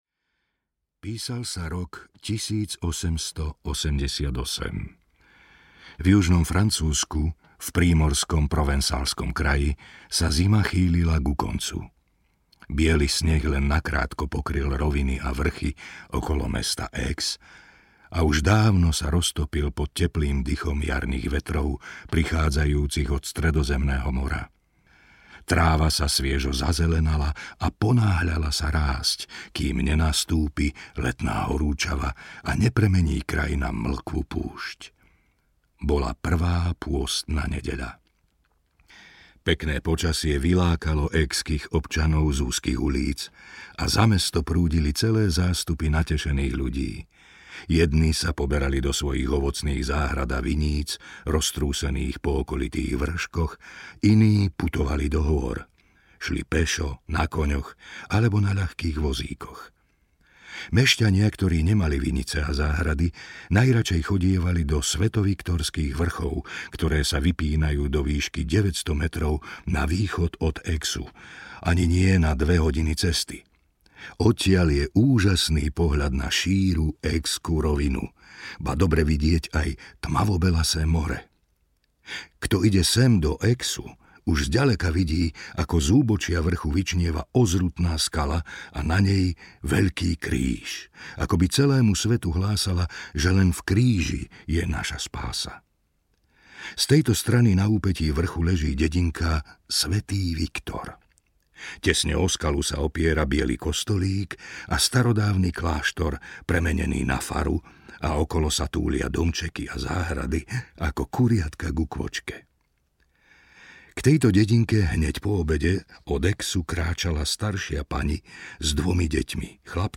Obeť spovedného tajomstva audiokniha
Ukázka z knihy